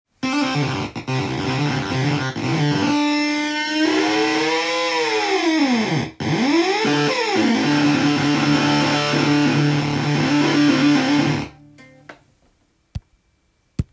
ファズは、潰れたような歪んだ音がするエフェクターですが
ZVEXのFuzz Factoryはギターをシンセに変える魔法のエフェクターです！
ノブを演奏しながら回すとよくわからない音も出ます！